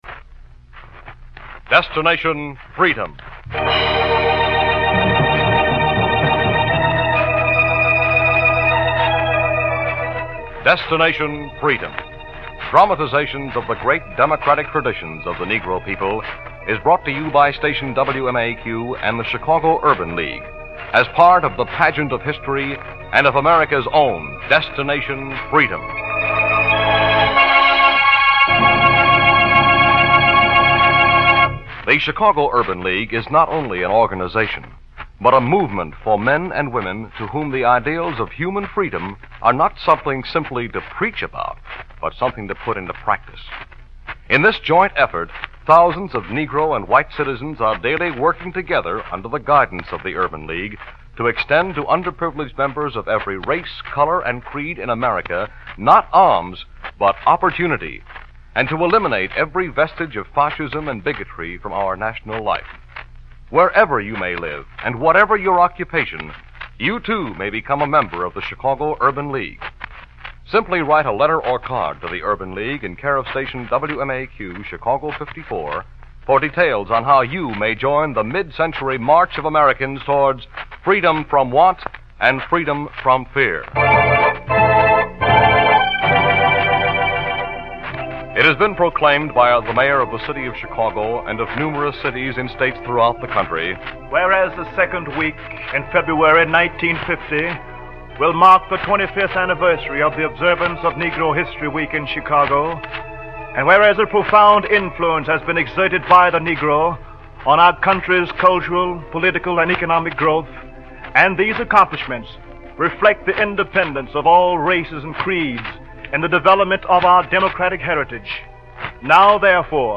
"Recorder of History" is an episode from the "Destination Freedom" series that aired on February 12, 1950. This series was known for its dramatizations of the lives and struggles of notable African Americans, highlighting their contributions to society and the fight against racial discrimination.